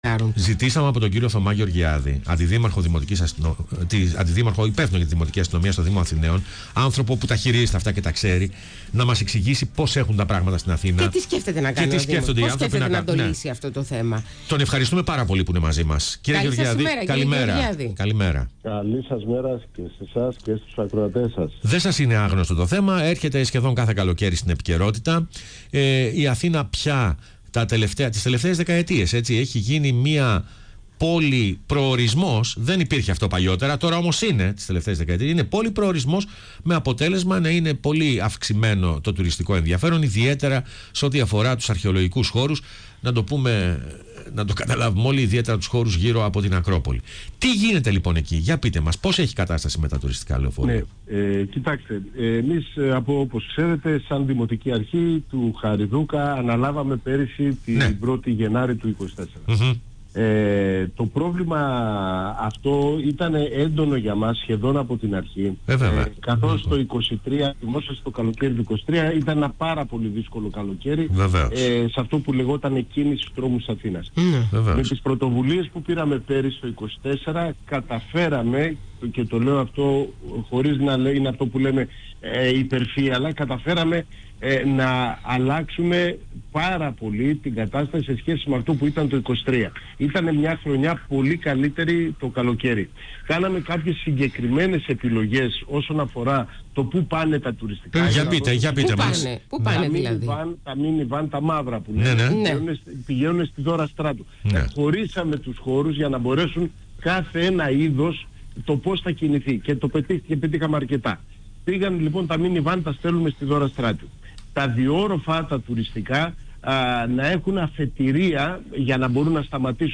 Ο Αντιδήμαρχος Δημοτικής Αστυνομίας του Δήμου Αθηναίων Θωμάς Γεωργιάδης μίλησε για το τεράστιο θέμα των τουριστικών λεωφορείων στο Κέντρο της Αθήνας στον ραδιοφωνικό σταθμό πρώτο πρόγραμμα